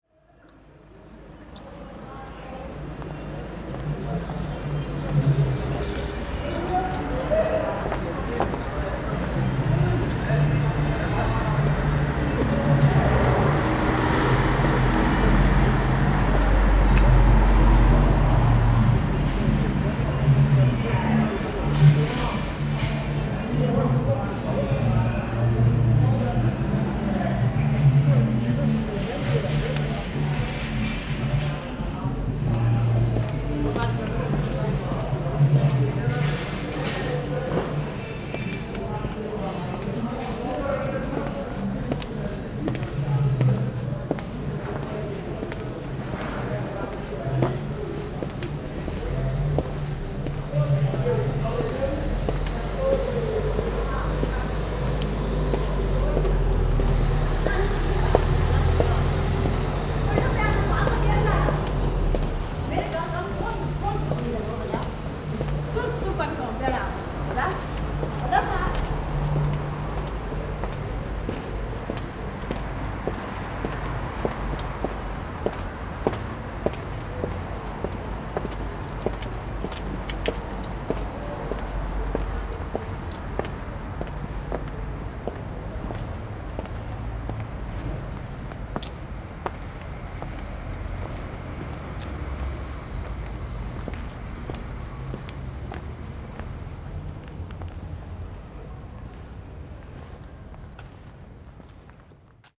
I made the sound design for the performance.
Sound files for one of the rooms (play them all at once in the browser or download and use a media player player to play them all at once):
Street sounds: